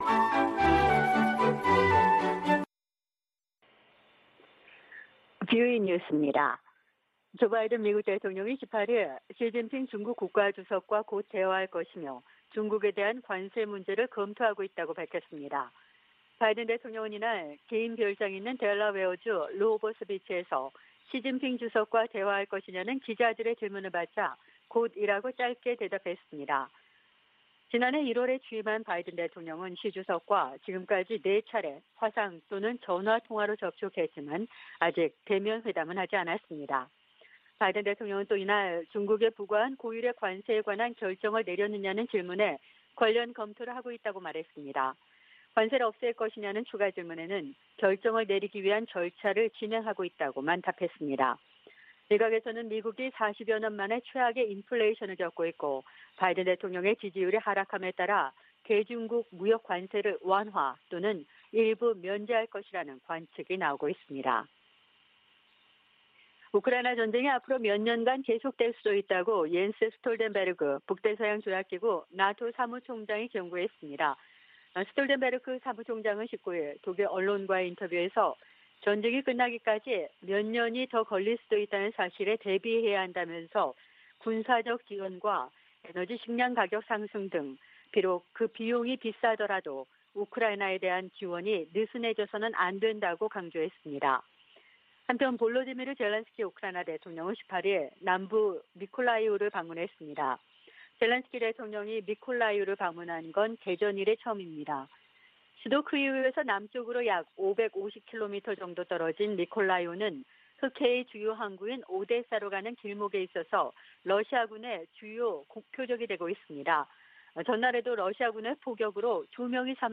VOA 한국어 방송의 일요일 오후 프로그램 4부입니다.